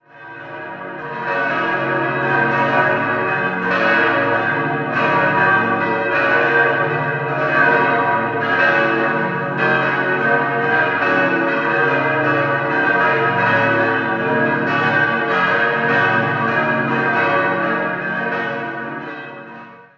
Das Kirchengebäude selbst ist dreischiffig angelegt und besitzt auch ein Querschiff. 5-stimmiges Geläute: b°-des'-es'-ges'-b' Nähere Daten liegen nicht vor.